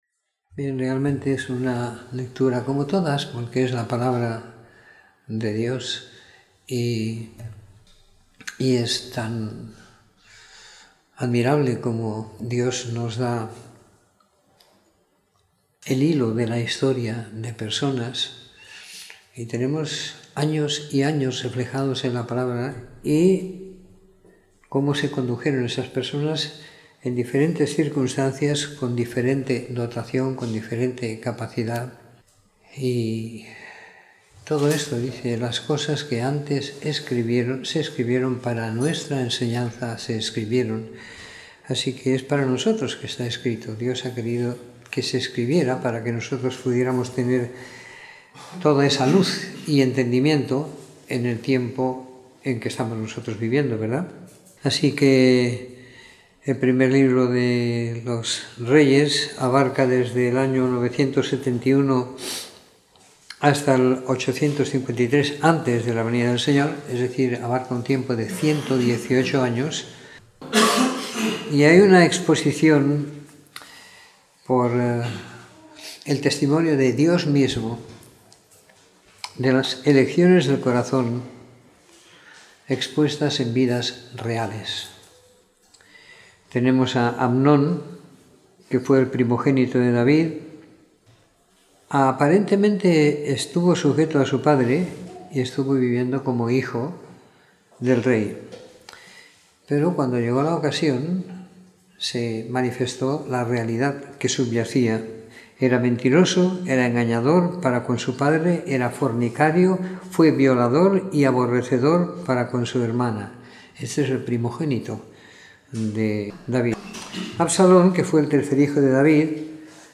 Comentario en el libro de 1º Reyes siguiendo la lectura programada para cada semana del año que tenemos en la congregación en Sant Pere de Ribes.